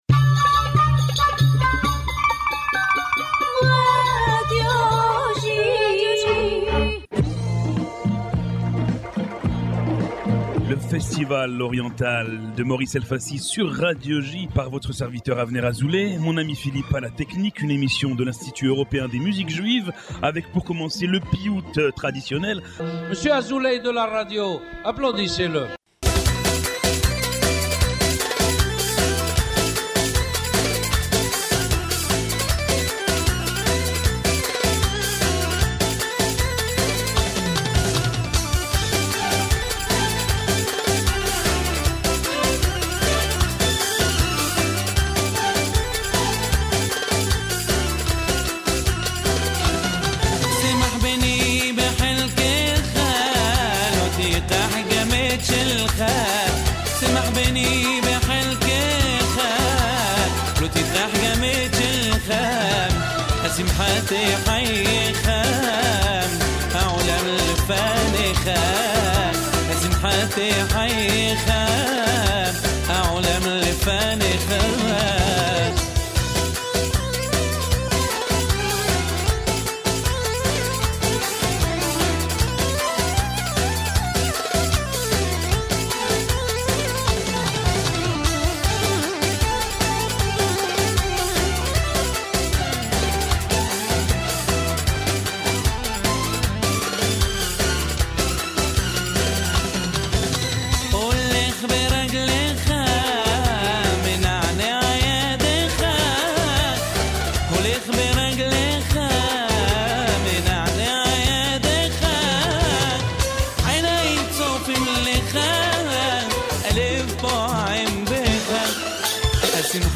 radio program
dedicated to Oriental Music